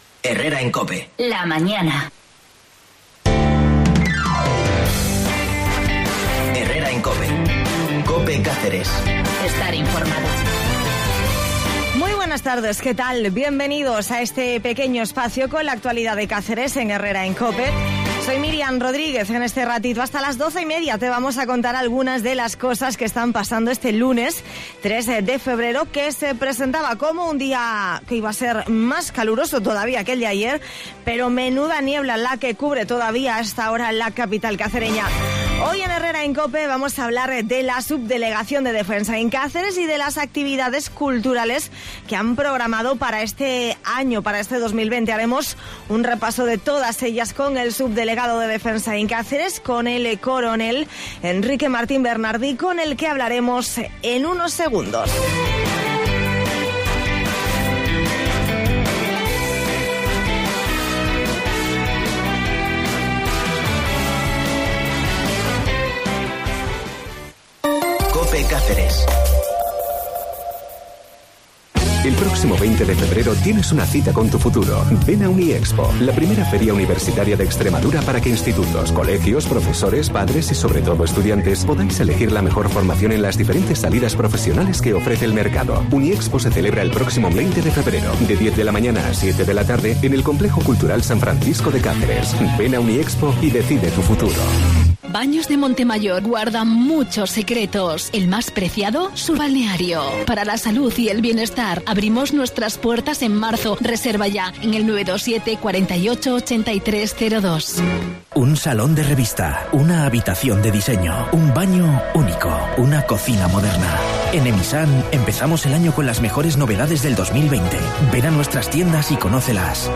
En Herrera en Cope vamos a hablar de la Subdelegación de Defensa en Cáceres y de las actividades culturales que han programado durante todo este año. Hacemos un repaso de algunas de ellas con el subdelegado de Defensa de Cáceres, el coronel Enrique Martín Bernardí.